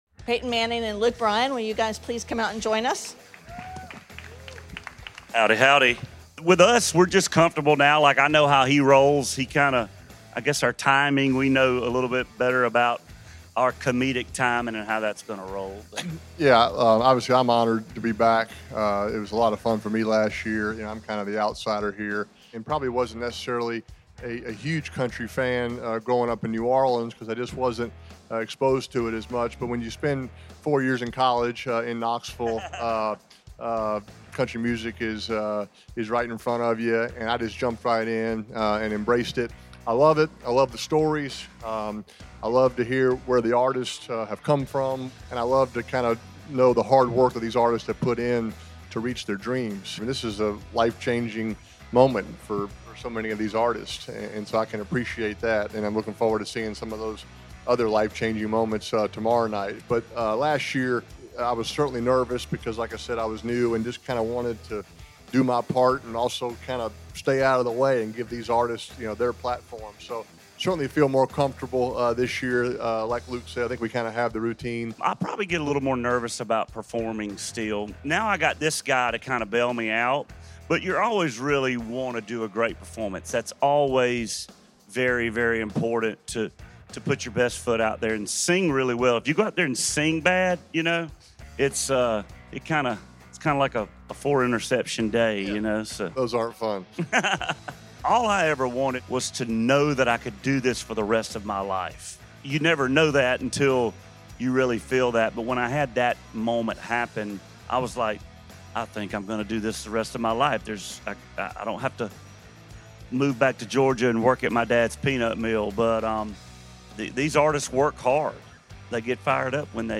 We caught up with Luke Bryan and Peyton Manning at a press conference a day ahead of the big event in Nashville to see what they have instore for us this year. The guys chat about this year’s surprise tributes, what’s going through the nominees minds, why they love country music and more.